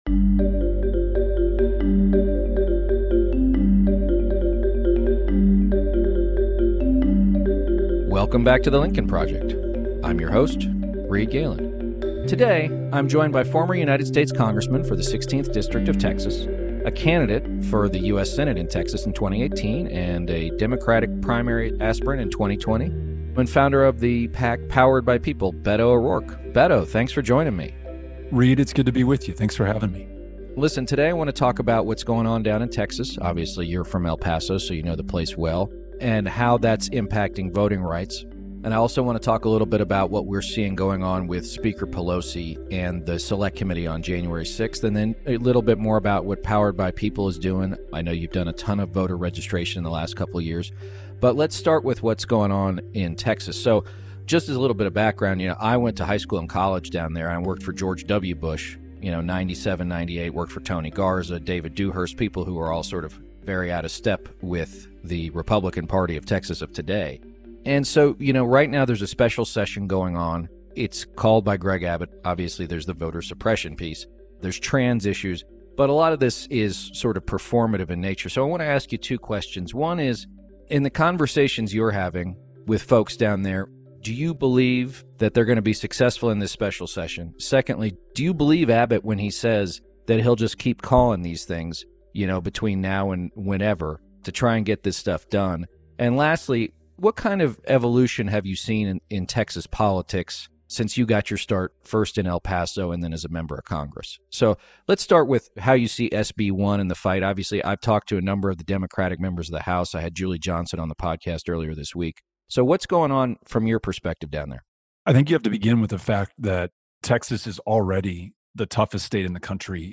Beto O’Rourke, former United States Congressman and Founder of Powered By People. They discuss the political landscape that is Texas, the latest regarding the Texas Legislature's voter-suppression bill and the 50+ Texas Democrats currently in Washington, and Speaker Pelosi’s decision to reject the additions of Jim Jordan and Jim Banks to the January 6th House Select Committee. Plus, Beto O’Rourke spotlights the work of his PAC, Powered By People.